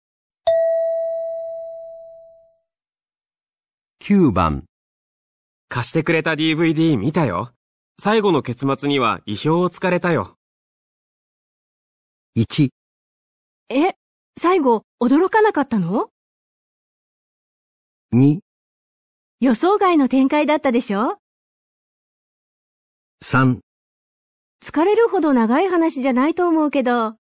男：